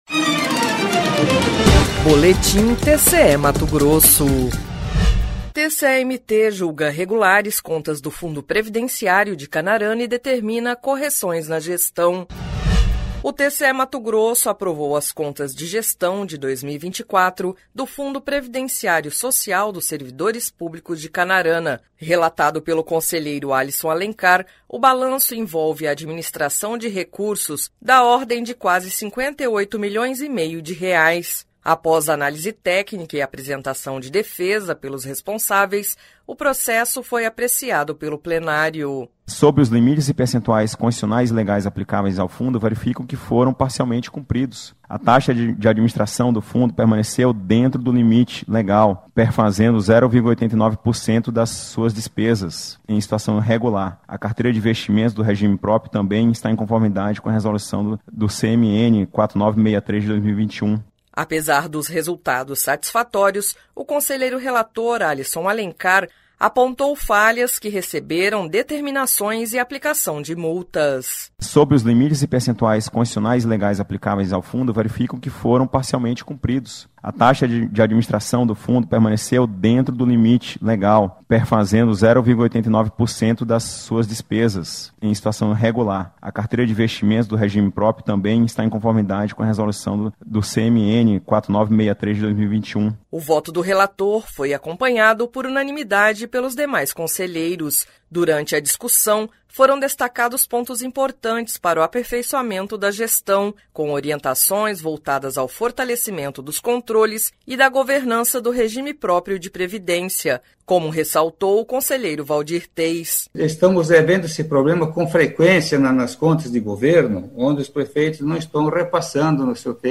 Sonora: Alisson Alencar – conselheiro do TCE-MT
Sonora: Waldir Teis – conselheiro do TCE-MT
Sonora: Guilherme Antonio Maluf – conselheiro do TCE-MT